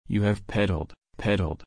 /ˈpɛdəl/